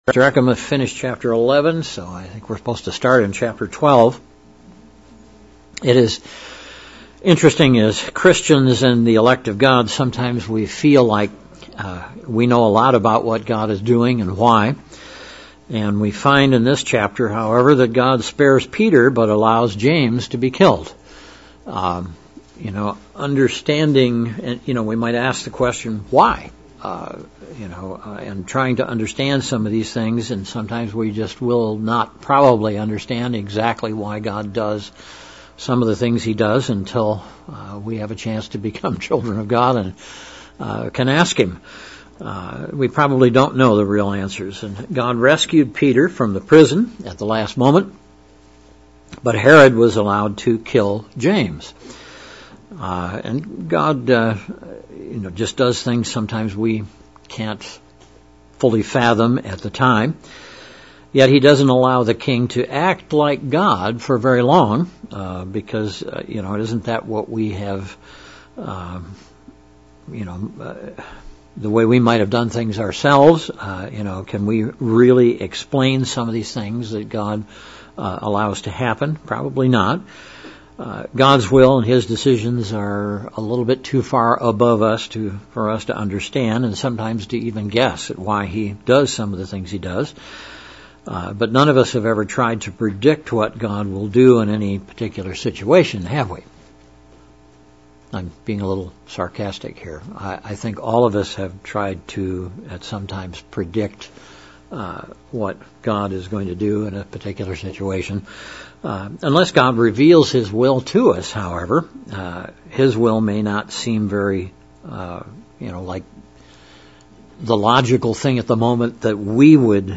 June Bible Study - Acts 11 & 12
Given in Central Illinois